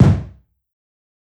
KICK_PAYME.wav